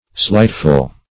Slightful \Slight"ful\